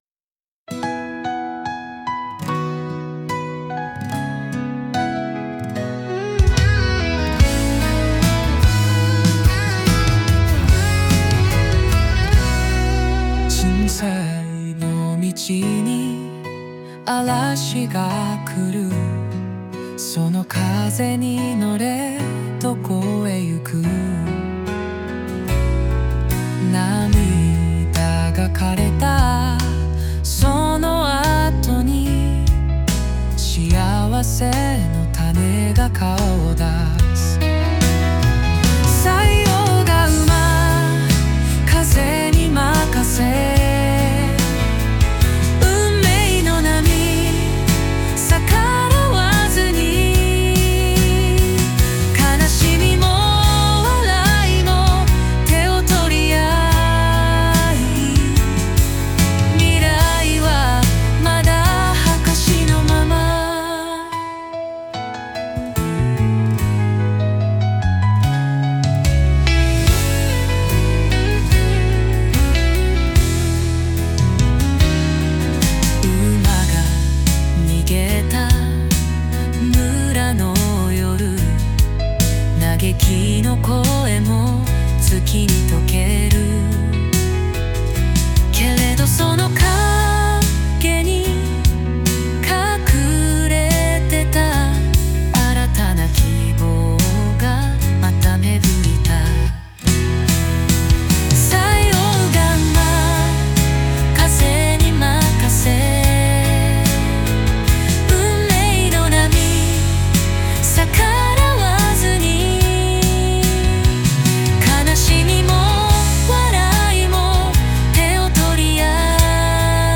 演奏はもちろん、ボーカルまでAIが担当してくれるので、
ジャンルは「アコースティック」系を選び、落ち着いた雰囲気に仕上げています。
メロディも自然で、歌声も温かみがあって、